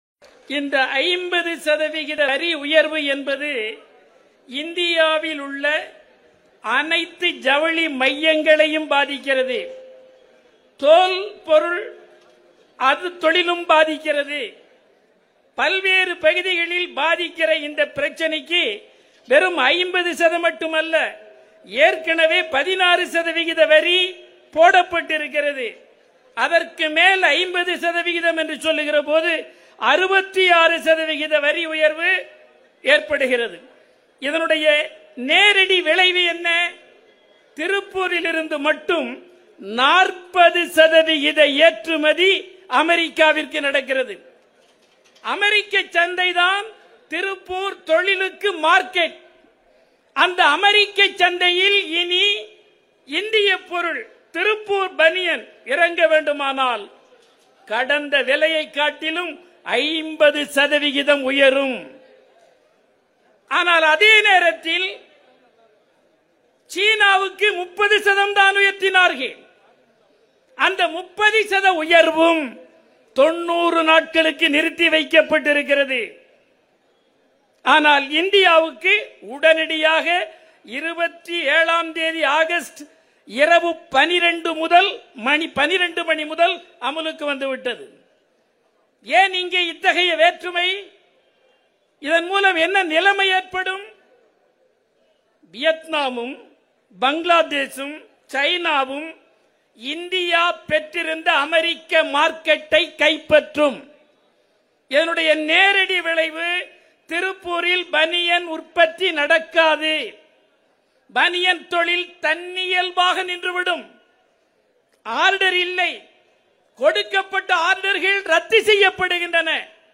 திருப்பூர் ஆர்ப்பாட்டத்தில் கே.சுப்பராயன் எம்.பி. பேச்சு
அமெரிக்காவின் 50 சதவீத வரிவிதிப்பை எதிர்த்து திருப்பூரில் நடைபெற்ற போராட்ட நிகழ்வில் மேலும் அவர் பேசியதாவது :